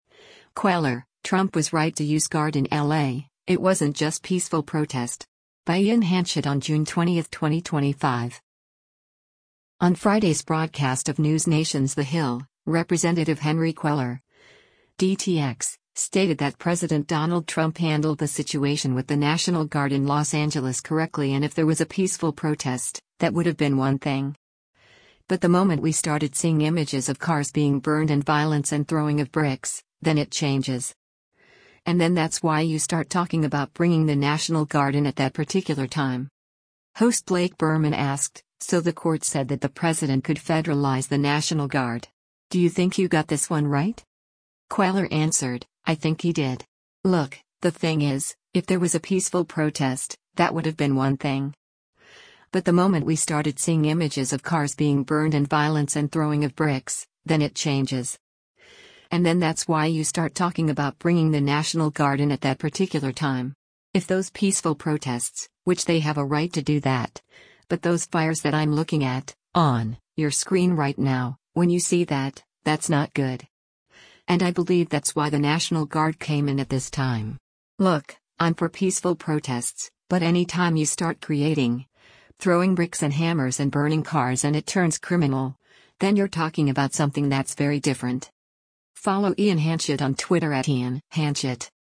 On Friday’s broadcast of NewsNation’s “The Hill,” Rep. Henry Cuellar (D-TX) stated that President Donald Trump handled the situation with the National Guard in Los Angeles correctly and “if there was a peaceful protest, that would have been one thing. But the moment we started seeing images of cars being burned and violence and throwing of bricks, then it changes. And then that’s why you start talking about bringing the National Guard in at that particular time.”